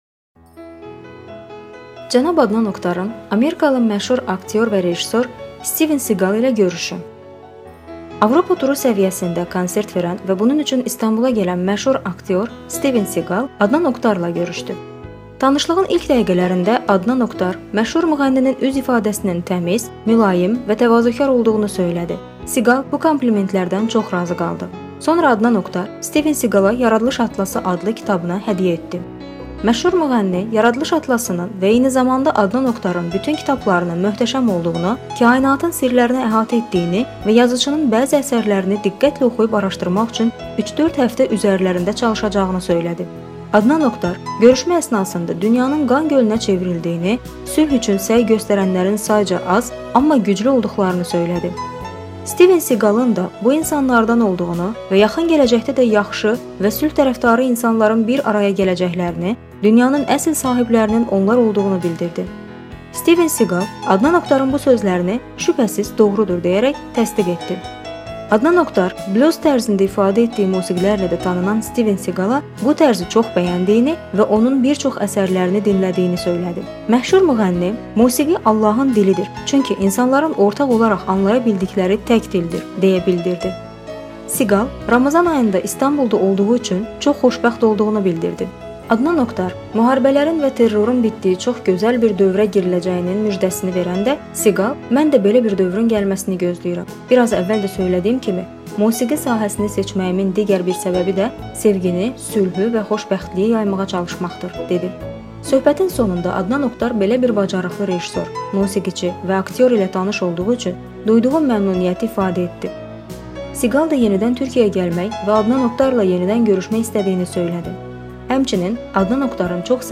Cənab Adnan Oktarın Amerikalı məşhur aktyor və rejissor Stivin Siqal (Steven Seagal) ilə görüşü
Avropa turu səviyyəsində konsert verən və bunun üçün İstanbula gələn məşhur aktyor Stivin Siqal Adnan Oktar ilə görüşdü.